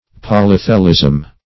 Search Result for " polythelism" : The Collaborative International Dictionary of English v.0.48: Polythelism \Pol`y*the"lism\, n. [Poly- + Gr. qhlh` a nipple.]